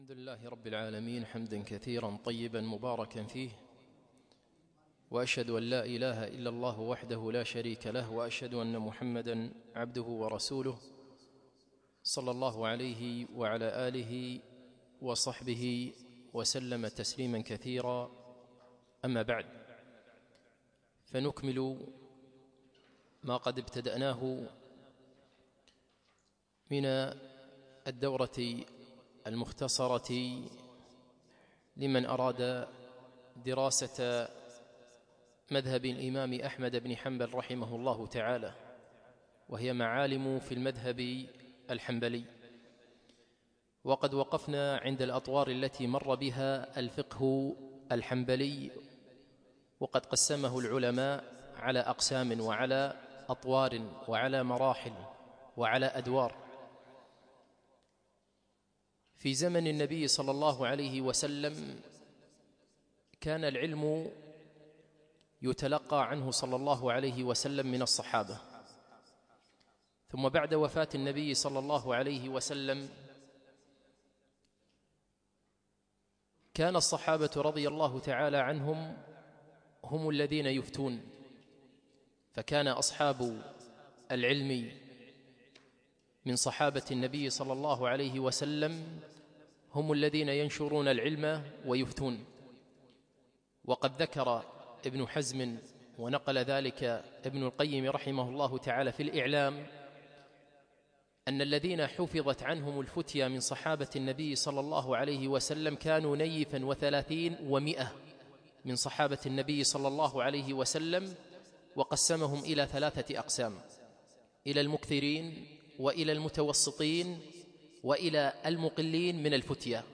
يوم الاثنين 9 جمادى الاول 1438 الموافق 6 2 2017 في مسجد العلاء بن عقبة الفردوس